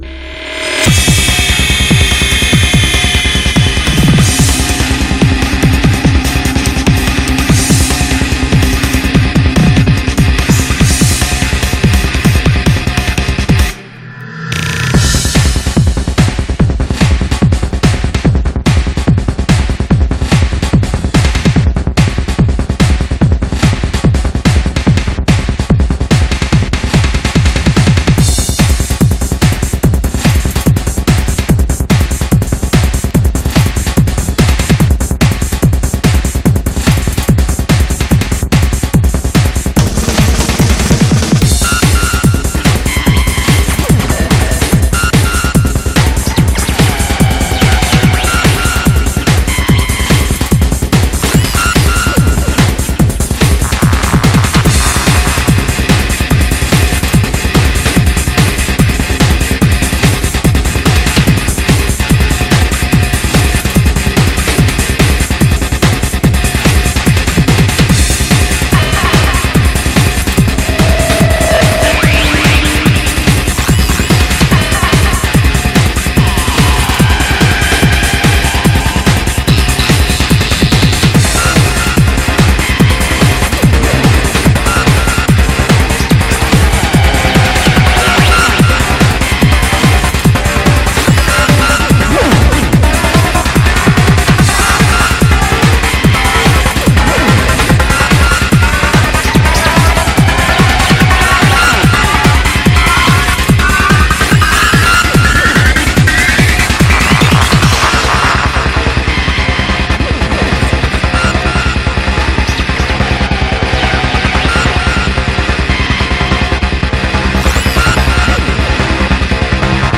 BPM73-580
Audio QualityPerfect (High Quality)
Cool psy-trance song!